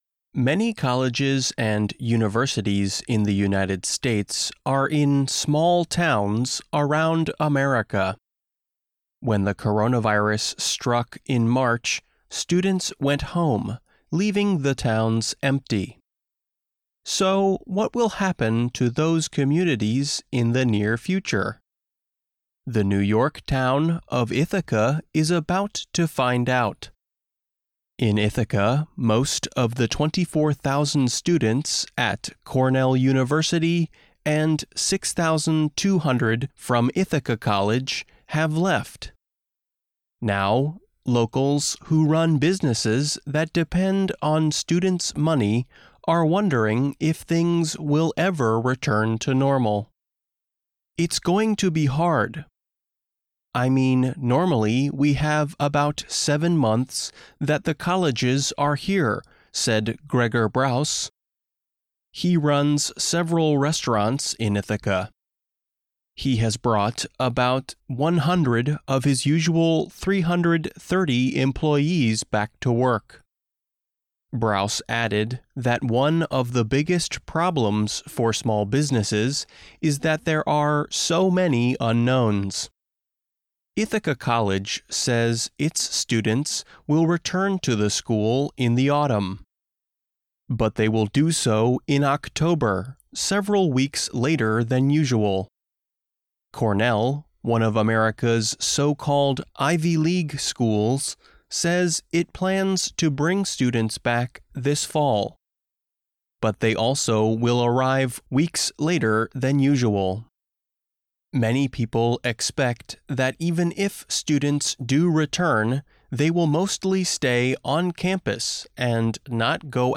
News
慢速英语:没有学生 美国的大学忧虑自己的未来